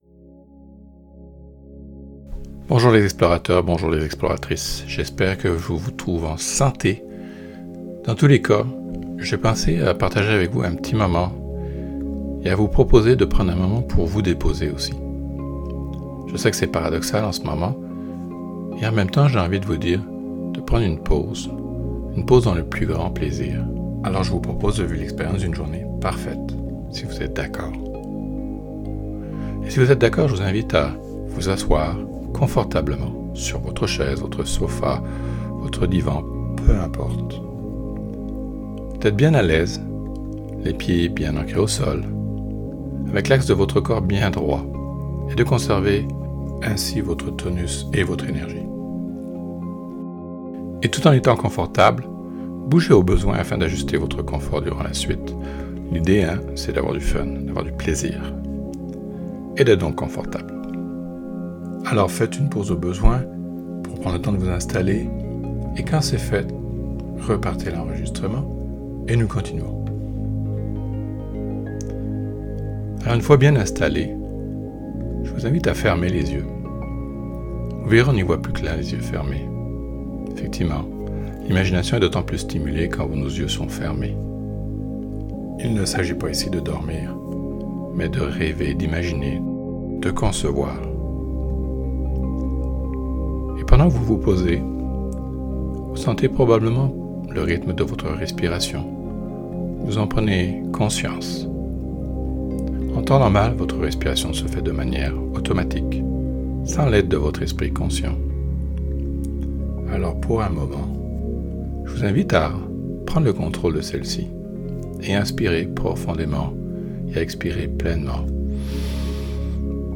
CAPSULES DE MÉDITATION ET D'HYPNOSE